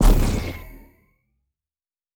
Sci Fi Explosion 06.wav